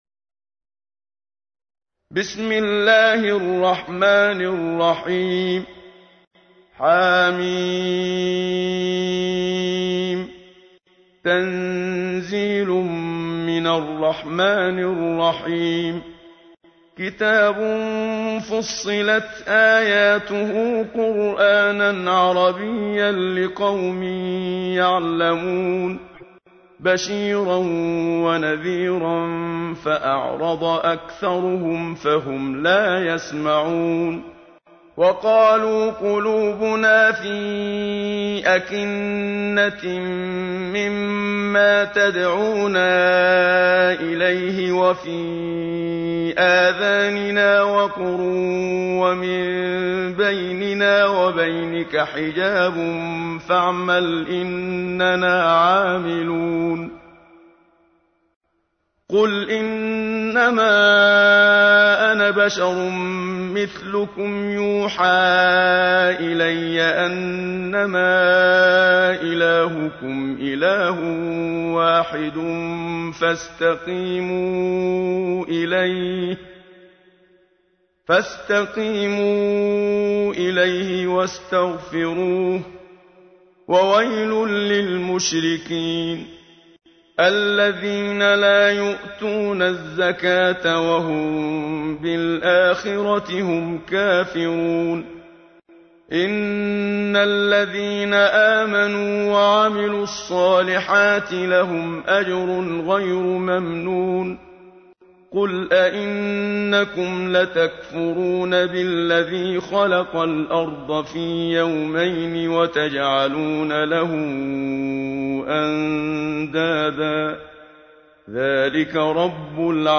تحميل : 41. سورة فصلت / القارئ محمد صديق المنشاوي / القرآن الكريم / موقع يا حسين